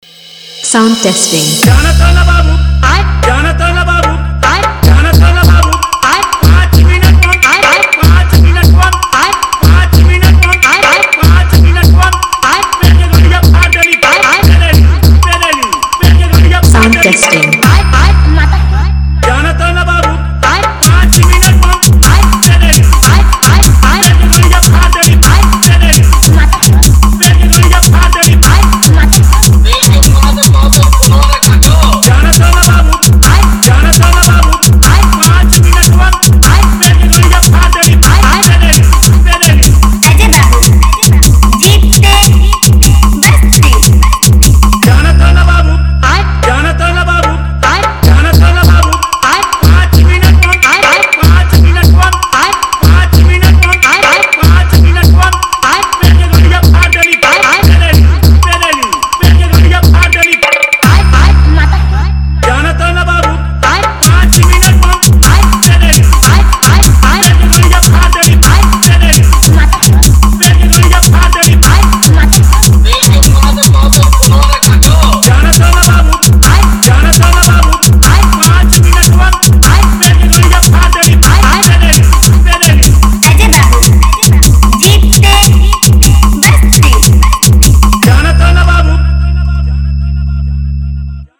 Category : Comptition Wala Dj Remix